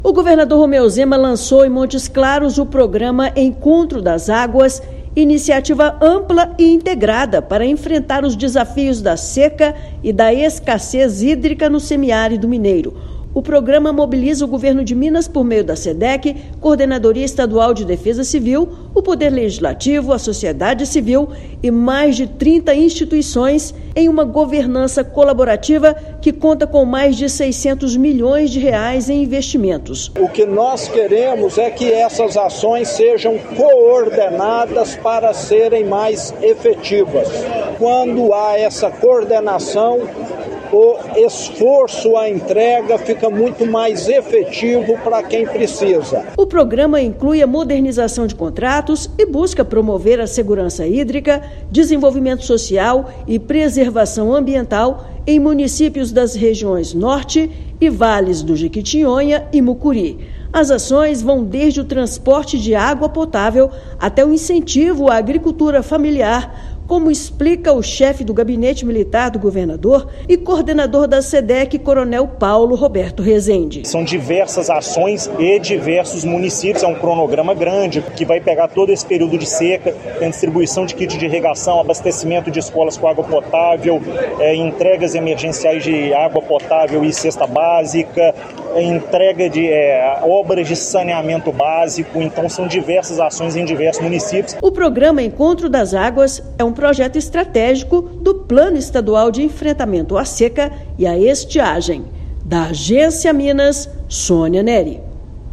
Reunindo mais de R$ 600 milhões em investimentos, iniciativa promove ações emergenciais e estruturantes para garantir segurança hídrica e contribuir para um semiárido mais forte. Ouça matéria de rádio.